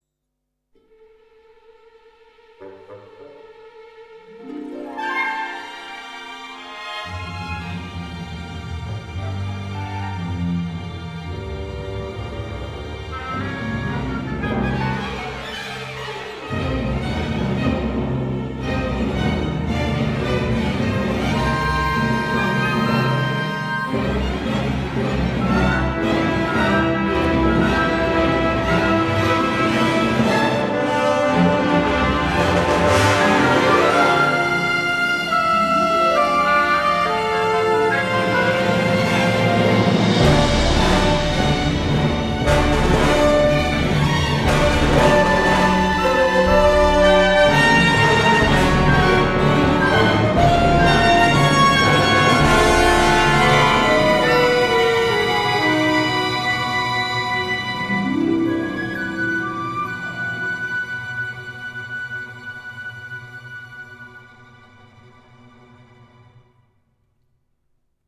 Frauenchor